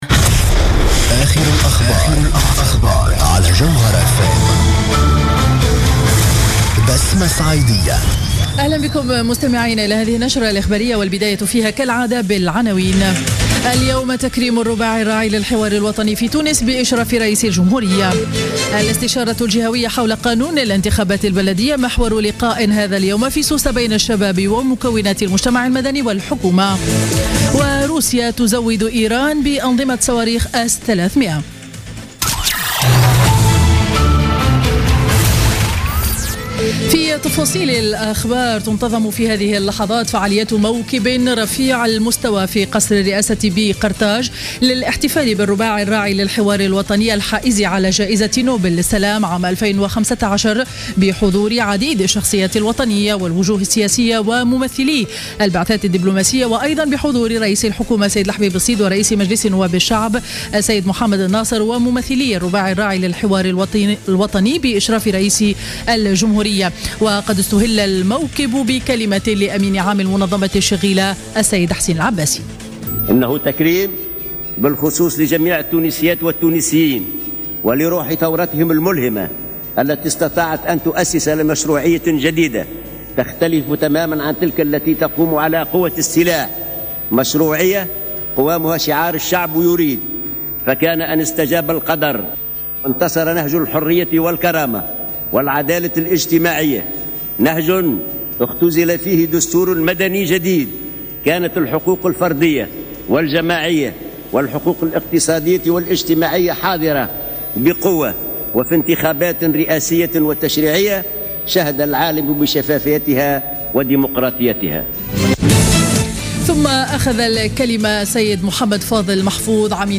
نشرة أخبار منتصف النهار ليوم الإثنين 9 نوفمبر 2015